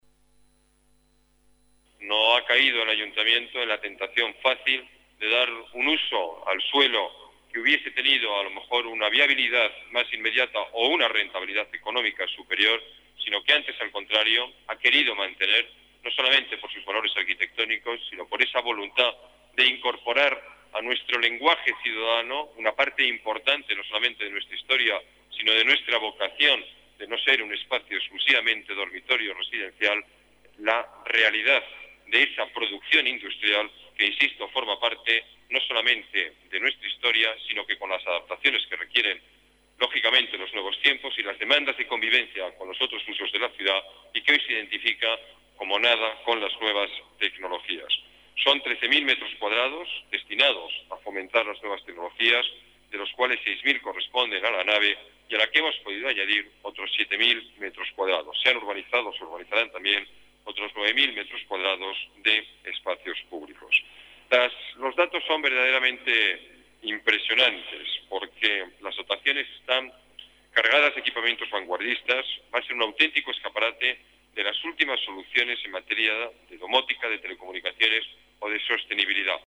El alcalde, Alberto Ruiz-Gallardón, habla sobre el significado de la "catedral" de las nuevas tecnologías para Madrid Ruiz-Gallardón, explica cómo la ubicación de la "catedral" contribuye al equilibrio territorial de la ciudad